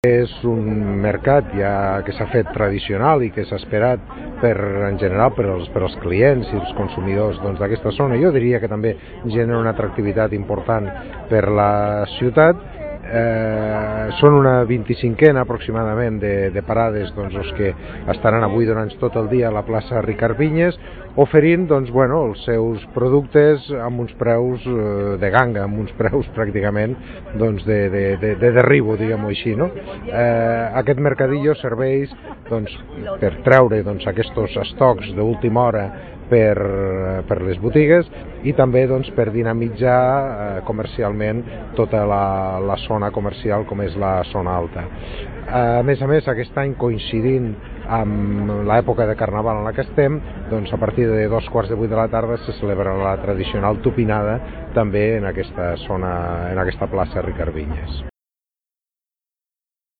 (tall de veu R. Peris)
tall-de-veu-del-tinent-dalcalde-rafael-peris-sobre-el-mercat-de-les-rebaixes-d2019hivern-de-la-zona-alta